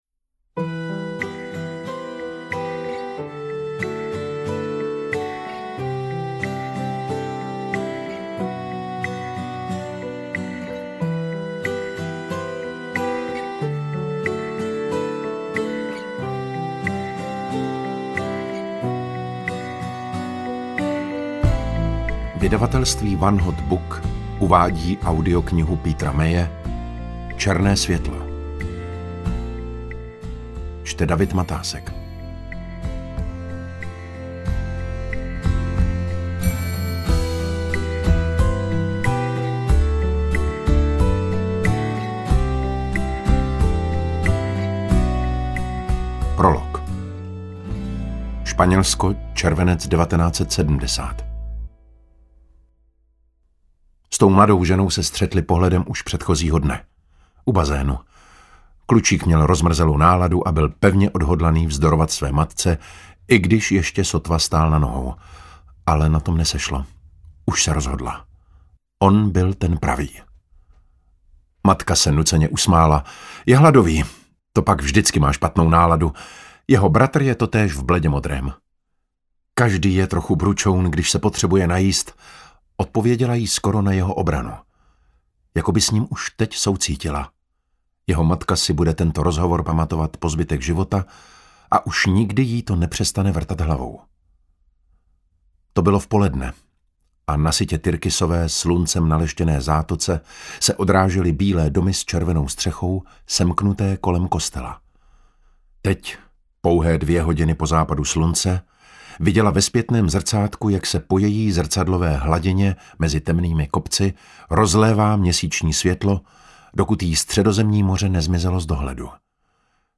Interpret:  David Matásek
AudioKniha ke stažení, 29 x mp3, délka 10 hod. 6 min., velikost 554,4 MB, česky